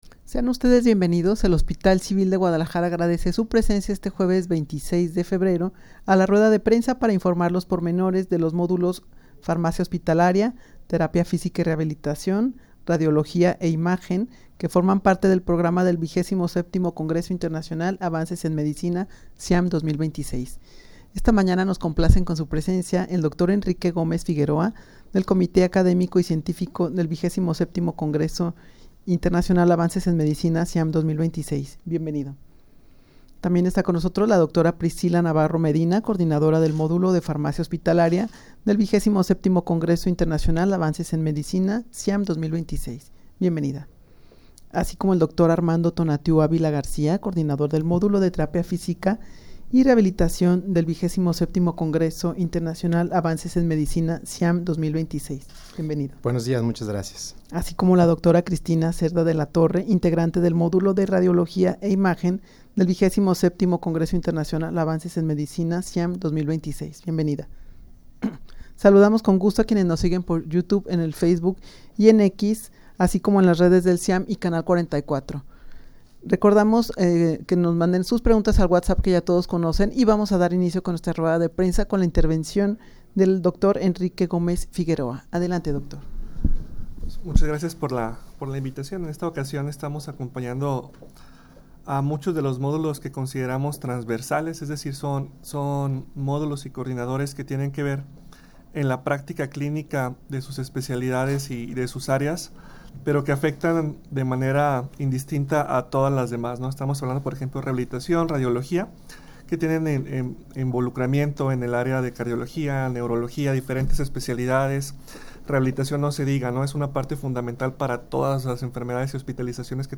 Audio de la Rueda de Prensa
rueda-de-prensa-para-informar-los-pormenores-de-los-que-forman-parte-del-programa-del-xxvii-ciam-2026.mp3